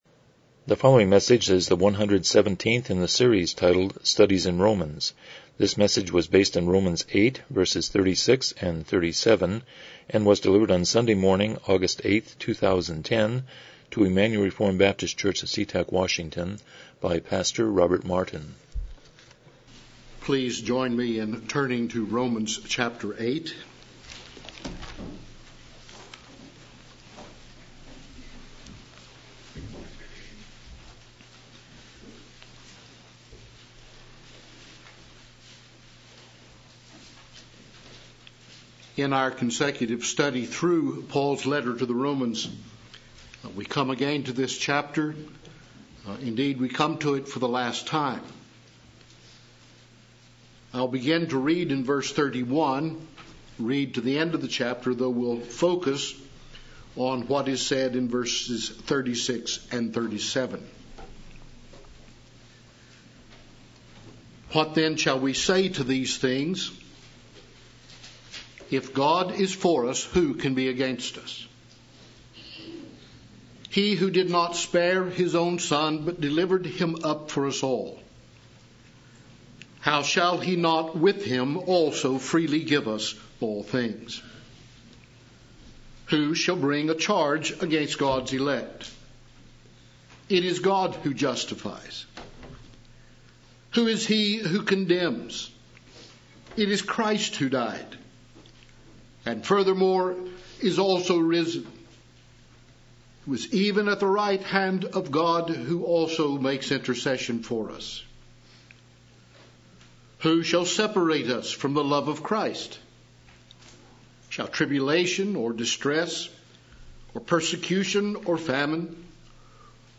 Romans 8:36-37 Service Type: Morning Worship « 102 Chapter 19.4